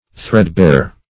Threadbare \Thread"bare`\, a.